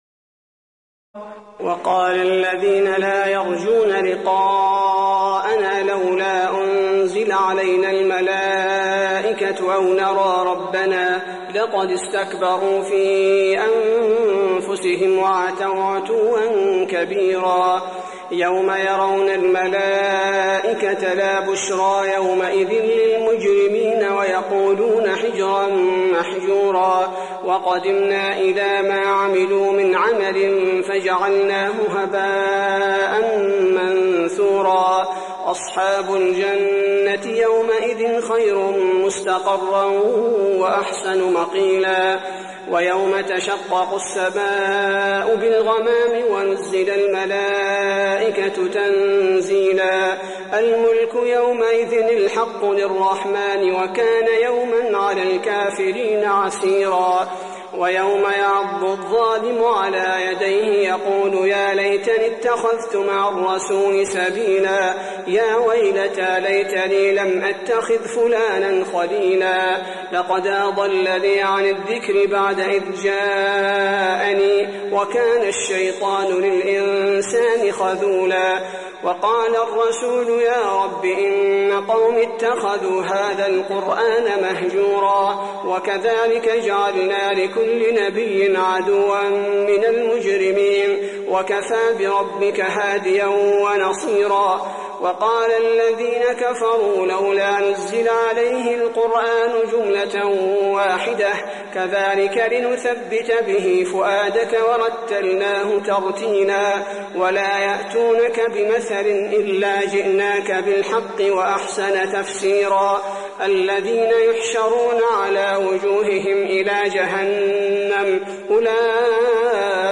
تراويح الليلة الثامنة عشر رمضان 1423هـ من سورتي الفرقان (21-77) و الشعراء (1-51) Taraweeh 18 st night Ramadan 1423H from Surah Al-Furqaan and Ash-Shu'araa > تراويح الحرم النبوي عام 1423 🕌 > التراويح - تلاوات الحرمين